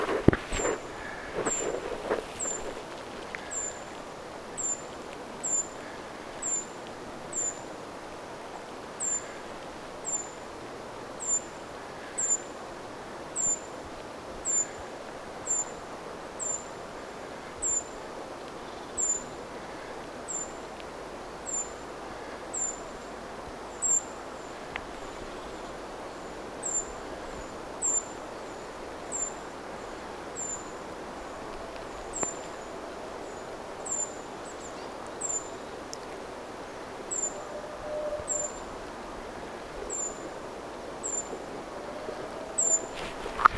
ti-puukiipijälintu ääntelee
ti-puukiipijalinnun_aantelya.mp3